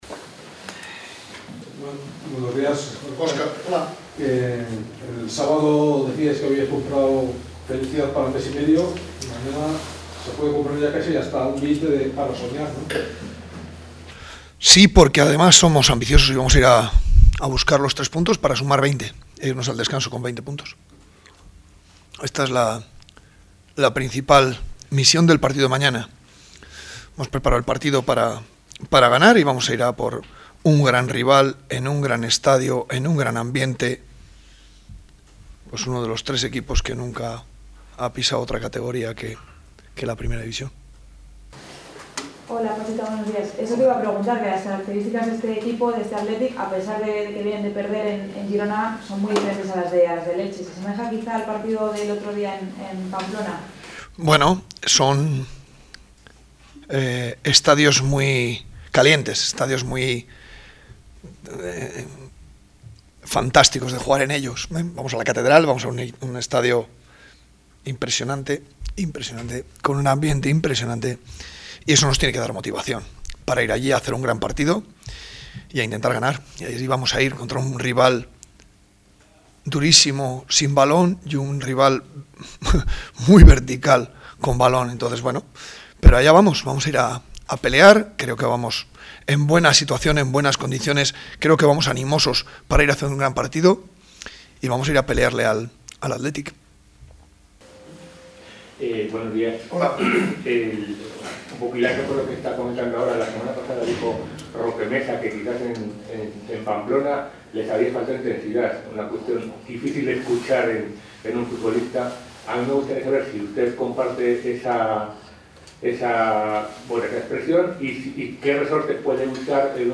Así lo ha expresado Pacheta en sala de prensa, en la previa de la jornada 14 en LaLiga Santander en la cual los castellanos visitan al Athletic en San Mamés.